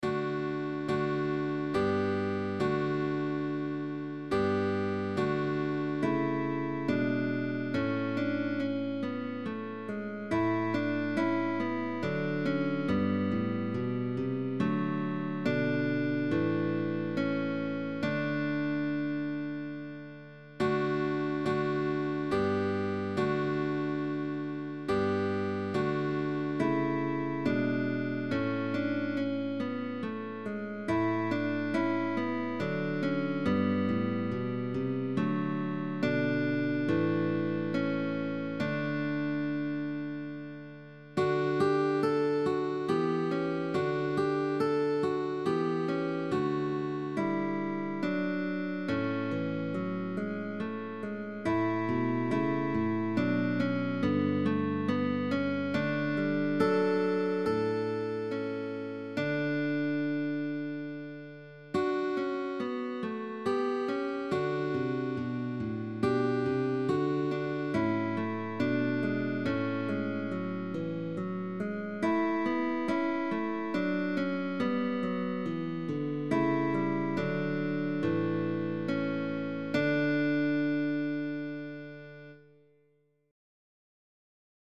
Early music